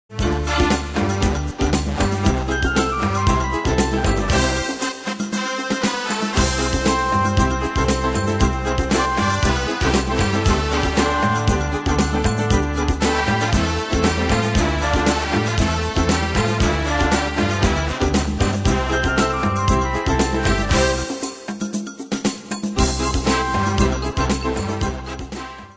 Style: Retro Ane/Year: 1959 Tempo: 117 Durée/Time: 2.45
Danse/Dance: Samba Cat Id.
Pro Backing Tracks